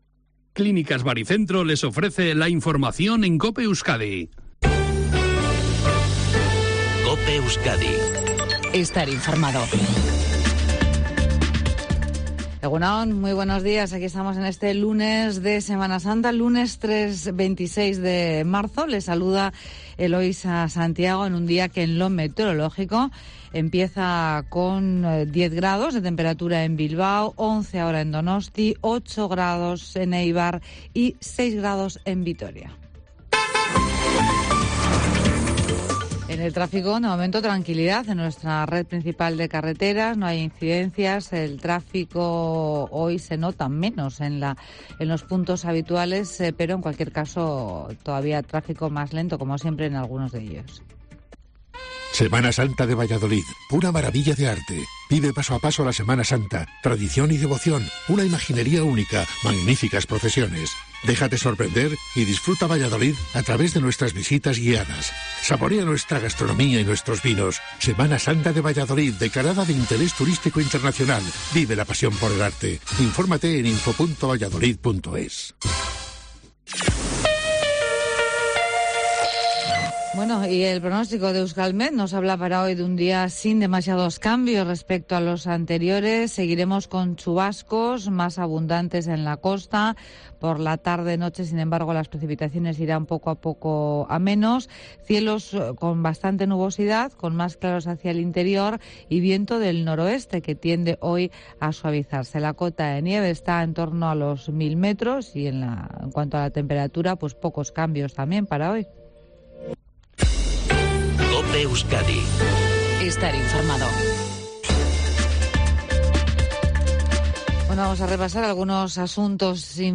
INFORMATIVO EUSKADI 7:50h